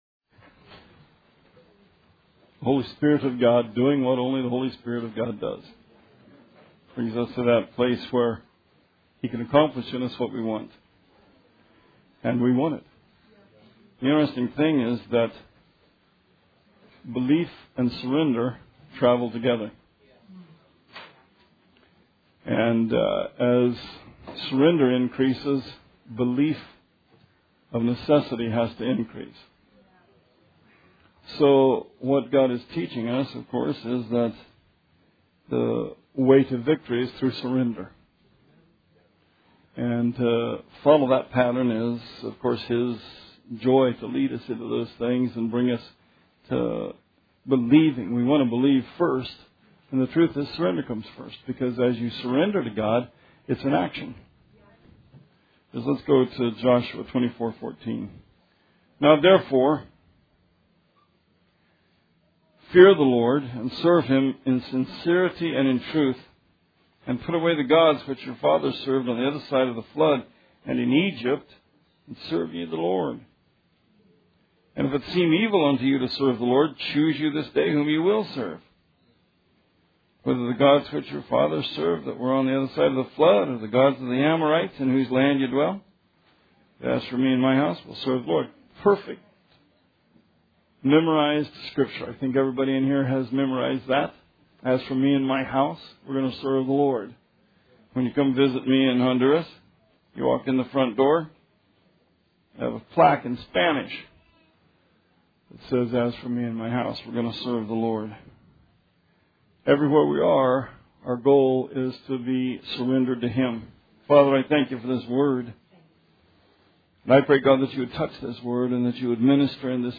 Sermon 4/9/17